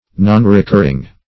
Search Result for " nonrecurring" : The Collaborative International Dictionary of English v.0.48: Nonrecurring \Non`re*cur"ring\, a. Nonrecurrent; as, the costs of a layoff are considered as a nonrecurring expense.